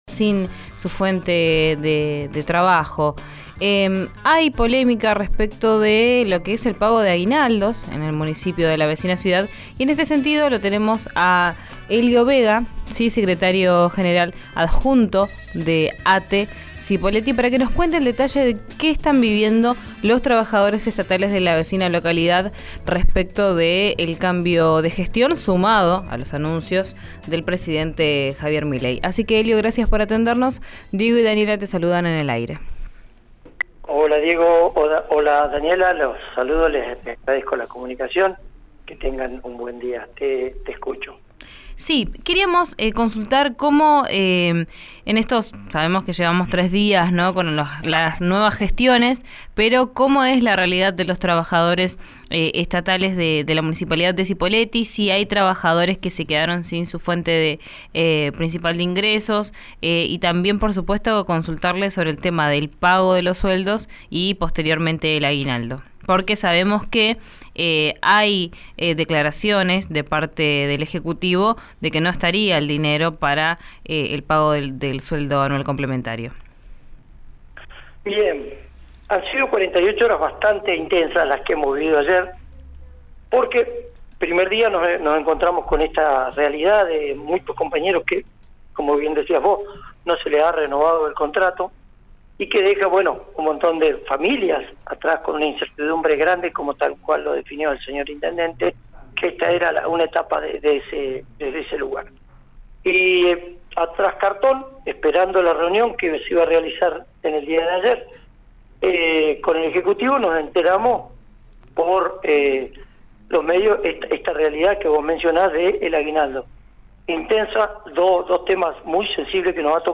Desde el gremio ATE, en diálogo con RÍO NEGRO RADIO, aseguraron que recibirán el pago del aguinaldo.